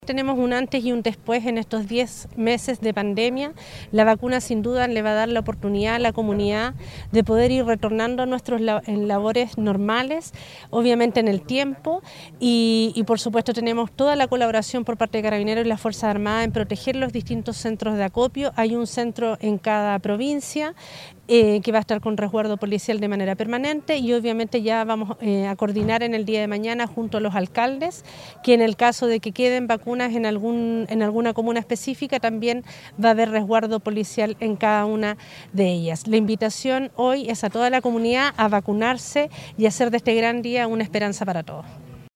La gobernadora provincial de Llanquihue Leticia Oyarce expresó que con este arribo de las vacunas, se marca un antes y un después en el contexto de la pandemia.
01-GOBERNADORA-LLANQUIHUE-.mp3